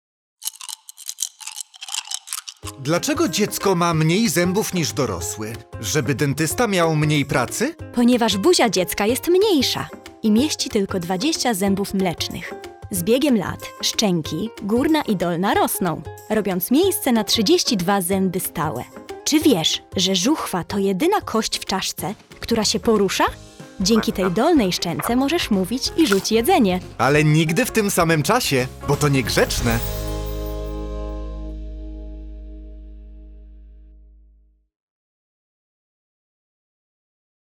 Odkryj zabawne rozmowy, pełne ciekawostek i odgłosów Twojego organizmu.